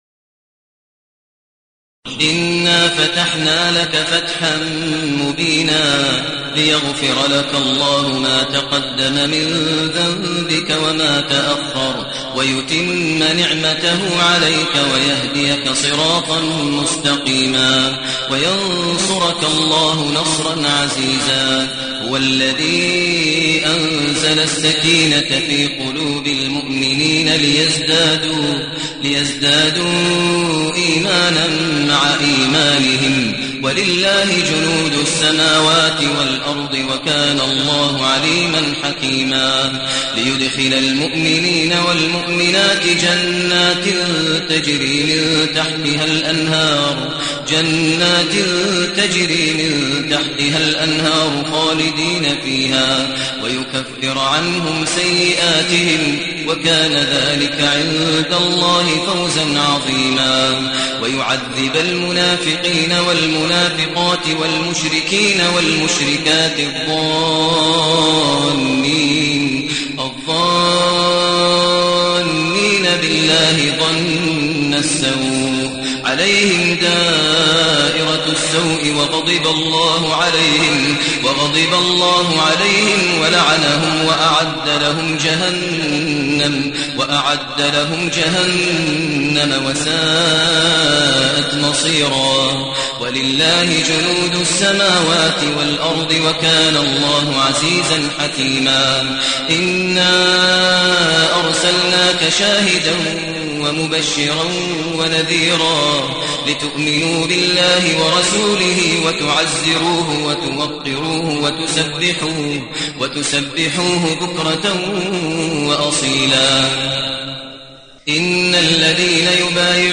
المكان: المسجد الحرام الشيخ: فضيلة الشيخ ماهر المعيقلي فضيلة الشيخ ماهر المعيقلي الفتح